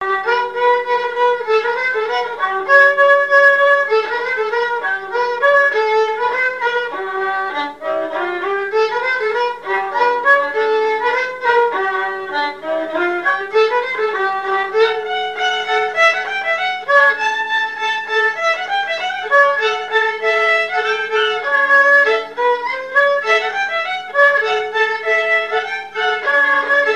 Chants brefs - A danser
danse : scottich sept pas
Pièce musicale inédite